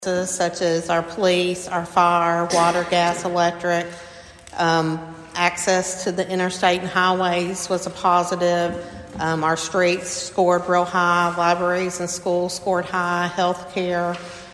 presented the results of the Comprehensive Plan Survey at Monday night’s City Council meeting.